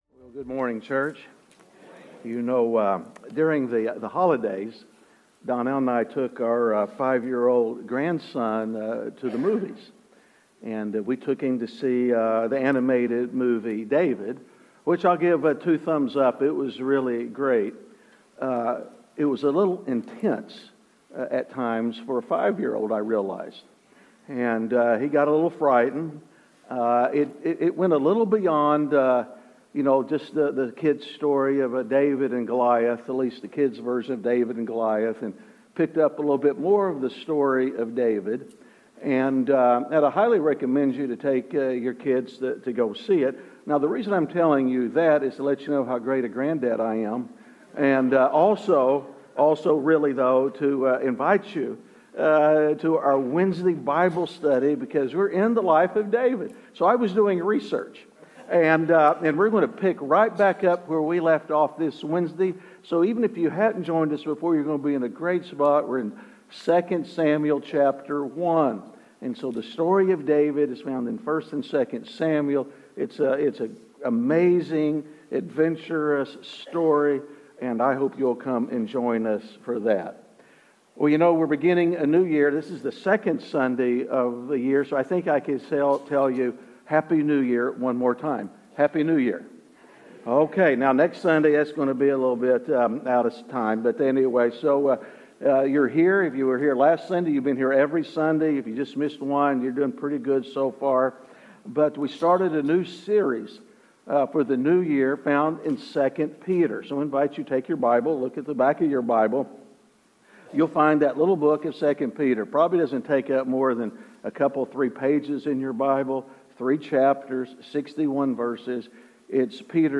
Messages | Bethany Church